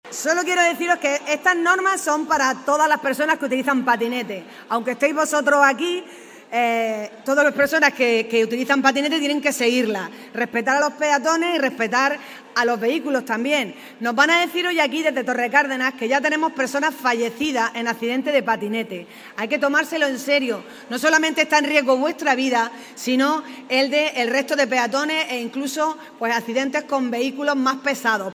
ALCALDESA-LLAMAMIENTO-AL-USO-RESPONSABLE-DEL-PATINETE.mp3